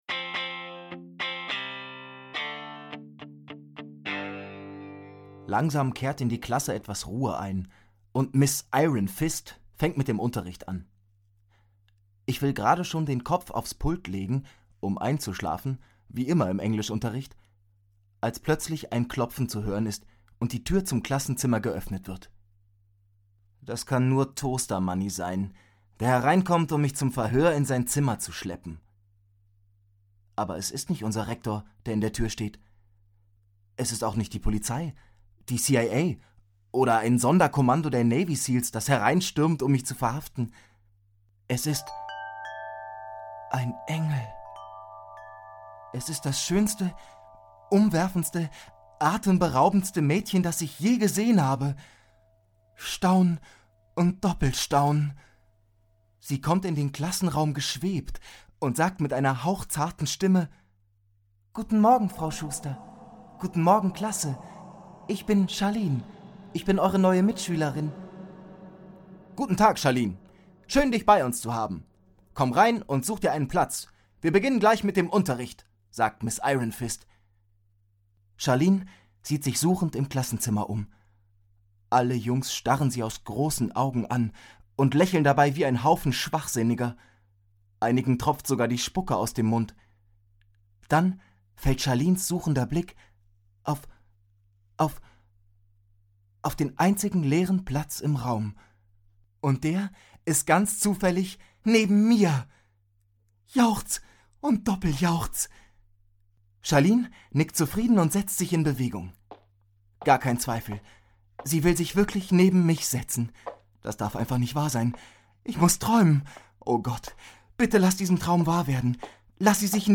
Hörbuch, 2 CD , ca. 150 Minuten